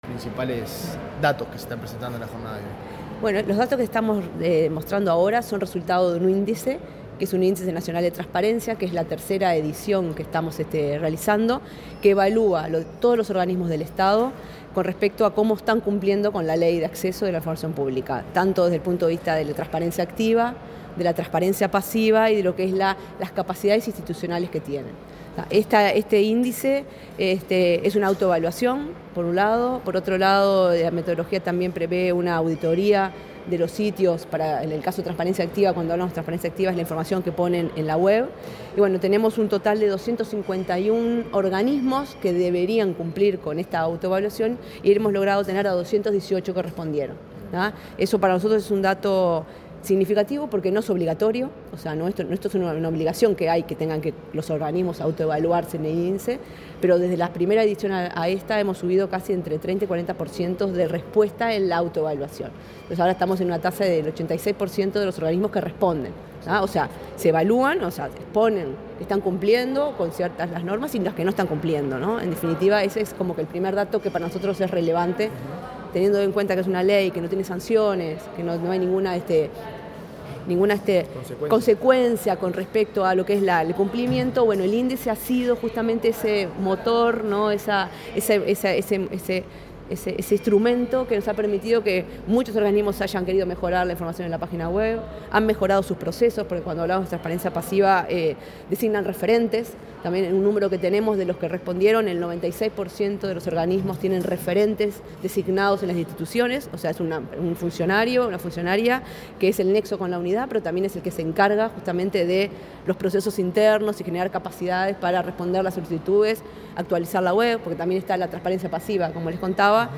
Declaraciones de la integrante del Consejo Ejecutivo de la Unidad de Acceso a la Información Pública, Virginia Pardo
La representante de Agesic en el Consejo Ejecutivo de la Unidad de Acceso a la Información Pública, Virginia Pardo, dialogó con los medios de prensa